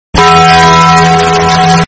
Taco Bell Bass Boosted Sound Button: Unblocked Meme Soundboard